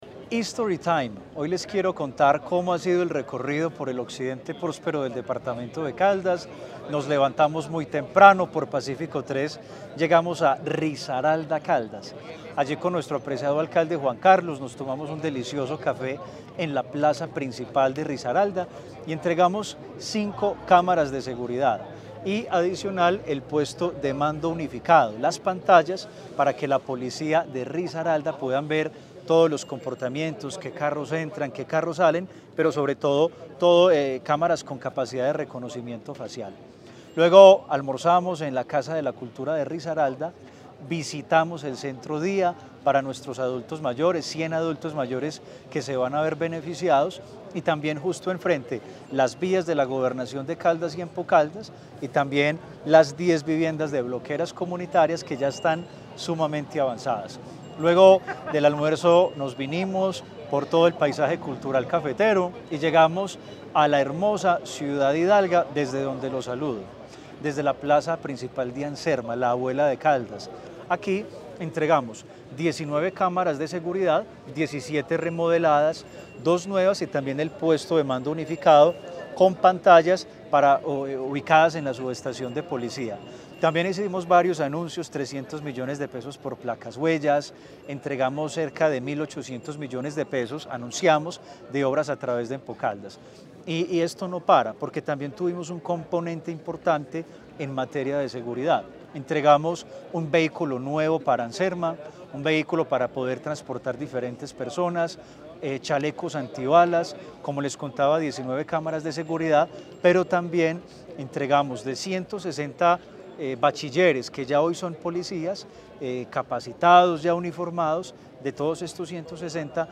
Gobernador de Caldas, Luis Carlos Velásquez Cardona.